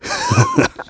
Clause_ice-Vox_Happy1.wav